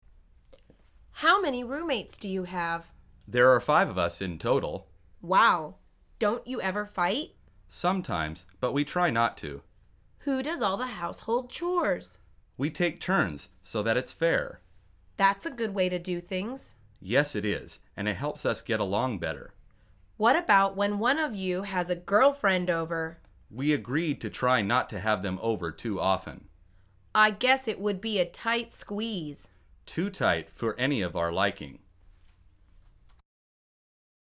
مجموعه مکالمات ساده و آسان انگلیسی – درس شماره هشتم از فصل مسکن: هم اتاقی